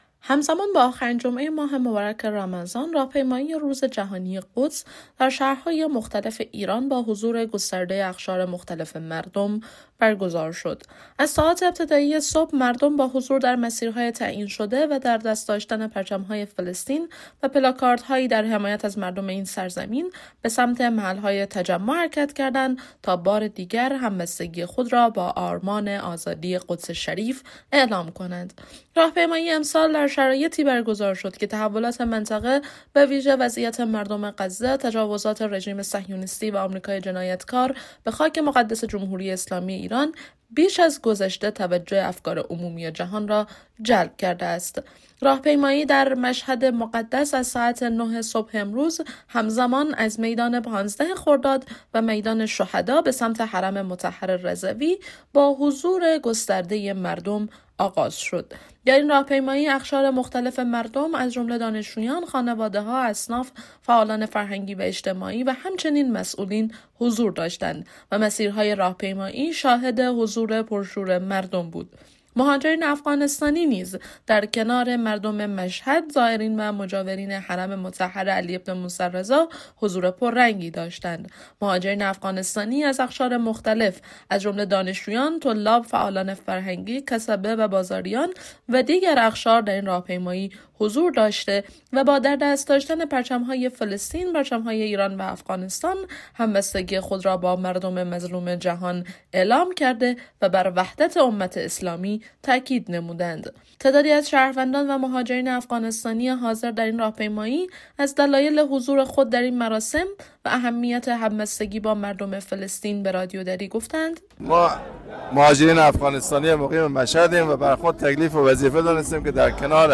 خبرنگار رادیو دری